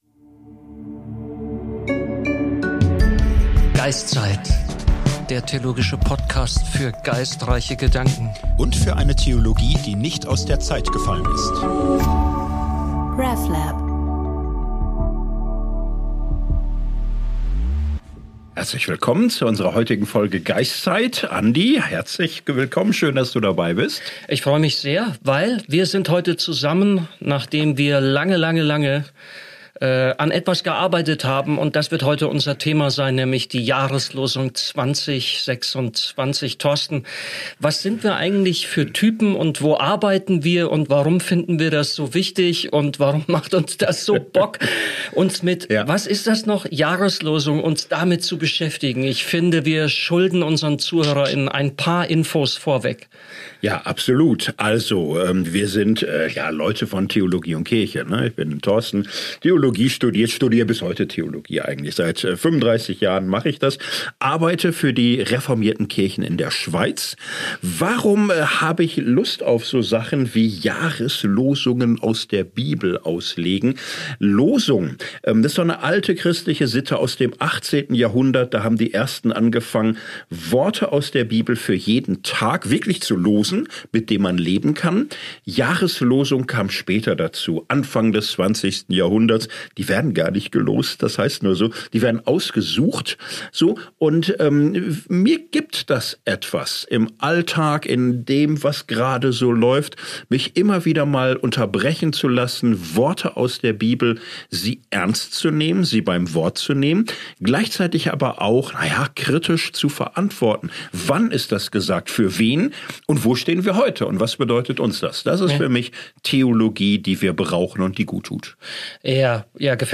Die beiden Theologen nähern sich der Jahreslosung auf dem Weg eigener Erfahrungen.